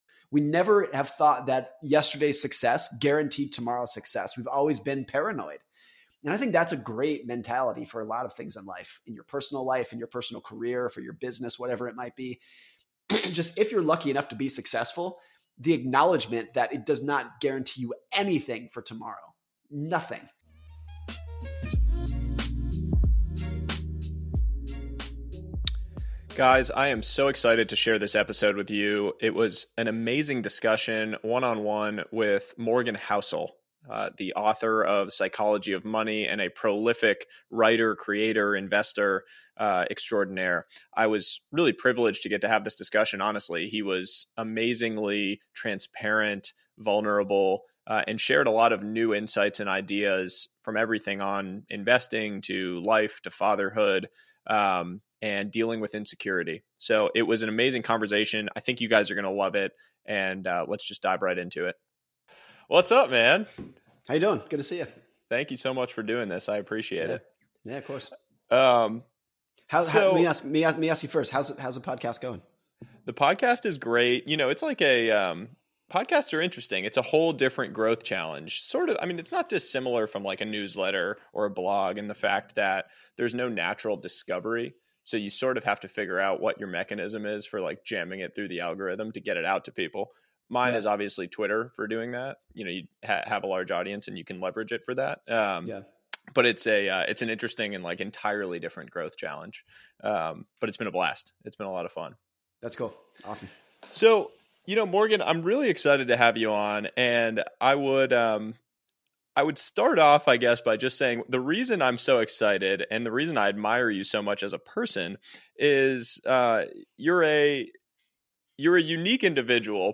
Guest Morgan Housel